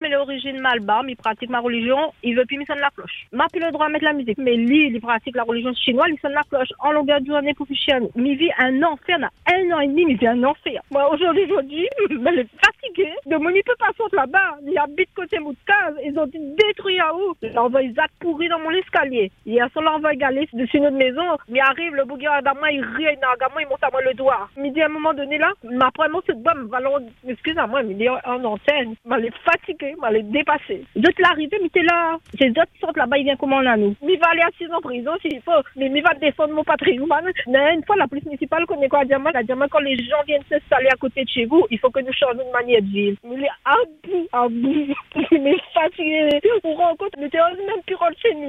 À Saint-Leu, une habitante parle d’un véritable cauchemar.